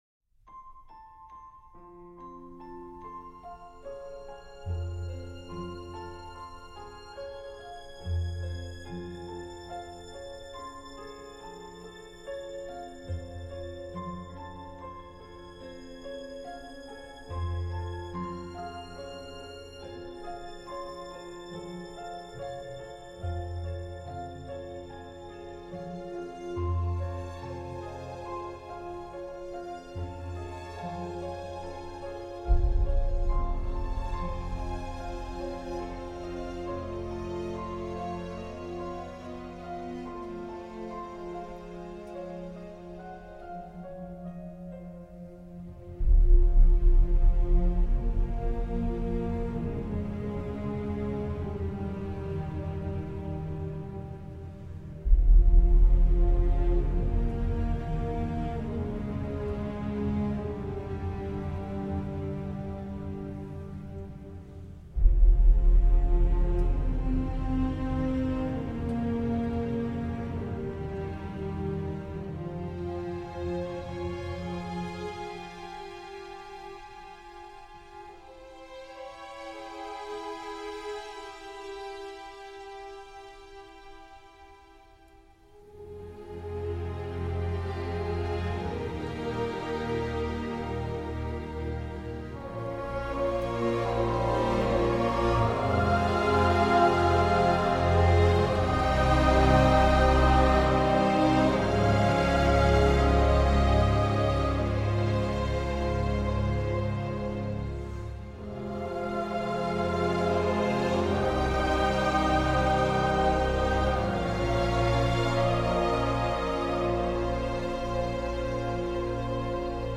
entre passages insipides et déflagrations furibardes.